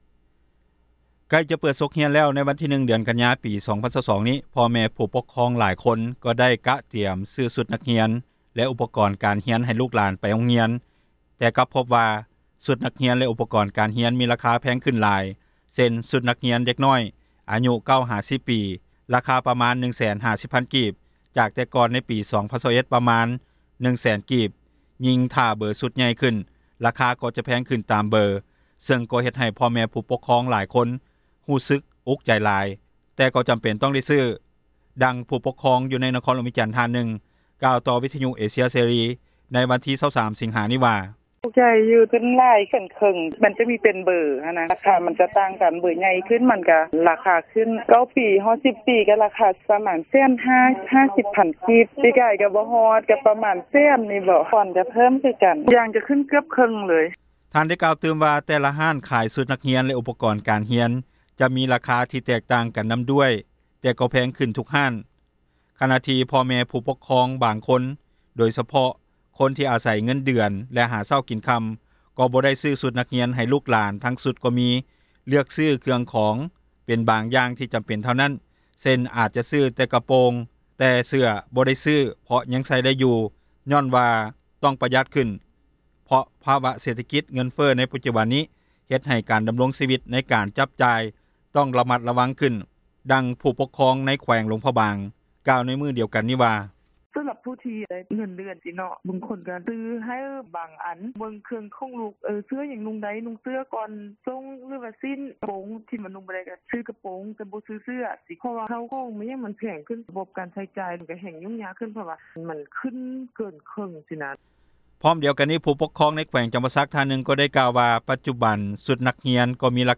ດັ່ງຜູ້ປົກຄອງ ໃນນະຄອນຫຼວງວຽງຈັນ ທ່ານນຶ່ງກ່າວຕໍ່ວິທຍຸເອເຊັຽເສຣີ ໃນວັນທີ 23 ສິງຫານີ້ວ່າ:
ດັ່ງຜູ້ປົກຄອງ ໃນແຂວງຫຼວງພຣະບາງ ກ່າວໃນມື້ດຽວກັນນີ້ວ່າ: